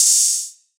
SOUTHSIDE_open_hihat_openlofi.wav